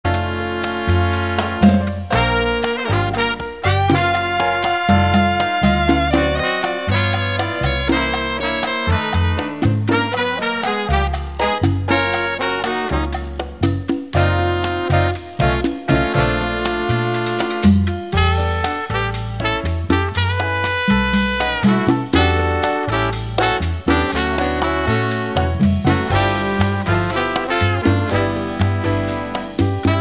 sassofono tenore
timbales